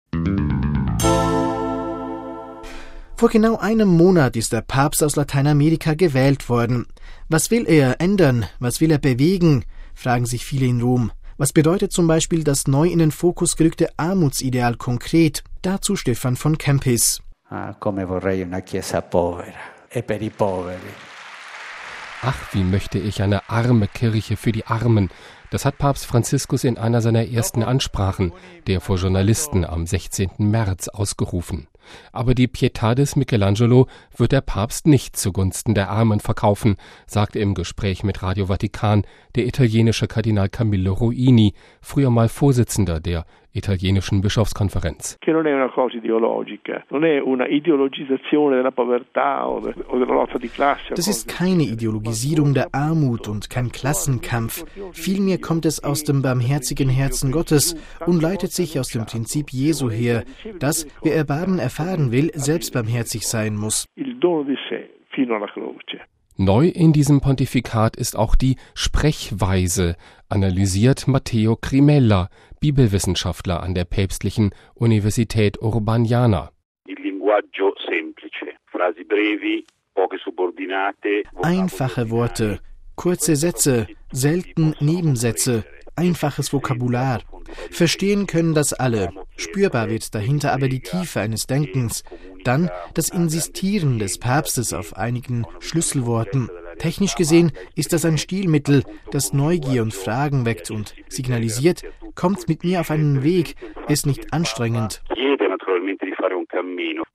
Aber die Pietà des Michelangelo wird der Papst nicht zugunsten der Armen verkaufen, sagt im Gespräch mit Radio Vatikan der italienische Kardinal Camillo Ruini, früher mal Vorsitzender der Italienischen Bischofskonferenz.